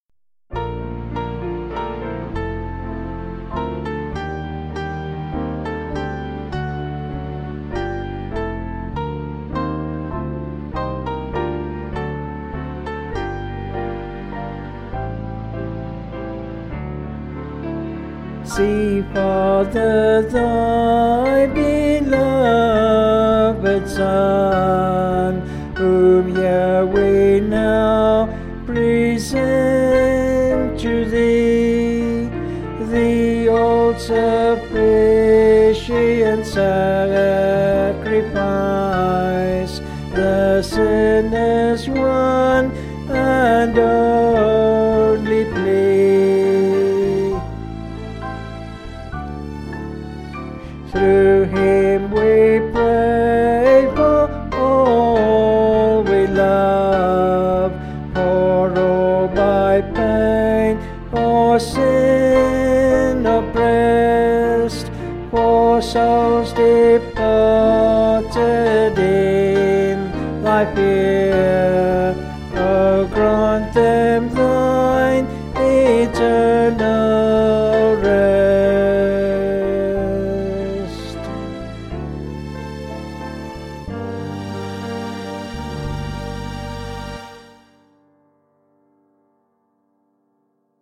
(BH)   2/Gm
Vocals and Band   264.7kb Sung Lyrics